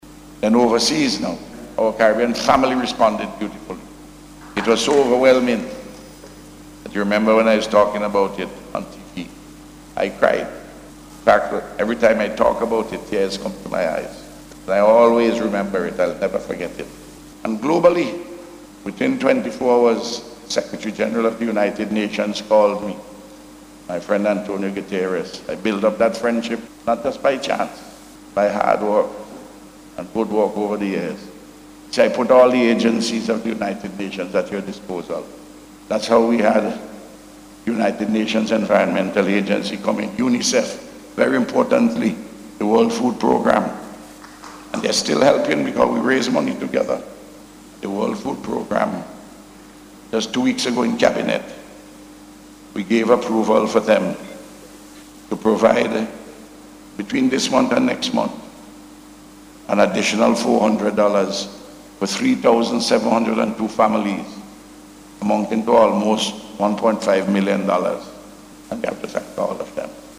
A Service of Thanksgiving was held at the Kingstown Baptist Church yesterday, as part of activities to observe Volcano Awareness Month Several Government Officials and representatives of State Agencies attended the Service, including Prime Minister Dr. Ralph Gonsalves.
In his address, Prime Minister Gonsalves expressed thanks to everyone who played a major role in the recovery effort, following the La Soufriere volcanic eruption.